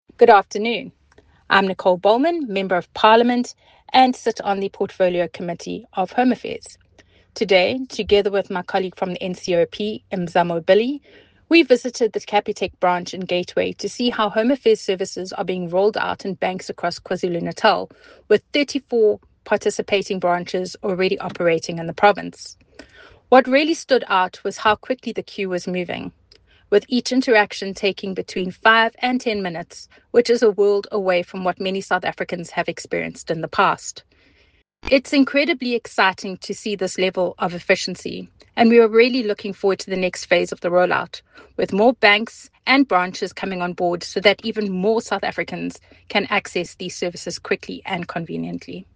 English by Nichole Bollman MP and
Nicole-Bollman-MP-English.mp3